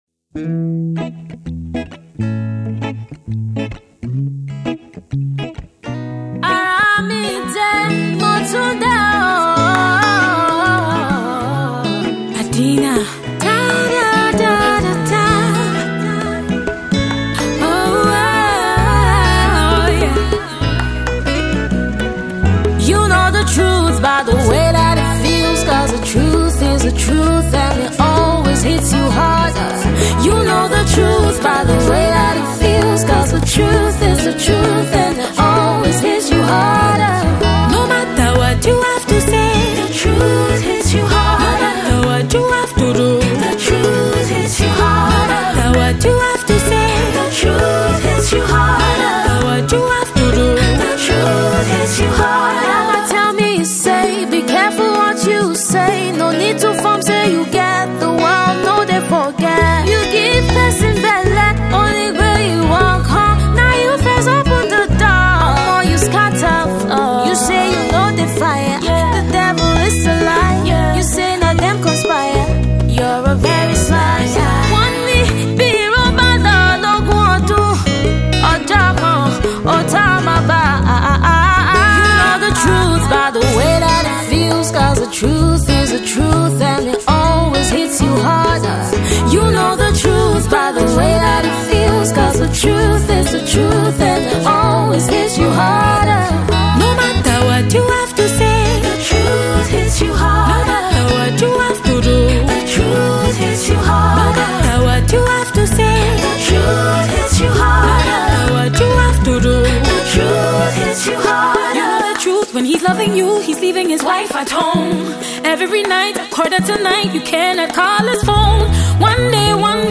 Afro-Soul Queen
Afro-pop star
with a mix of her native Yoruba
equally distinctive vocals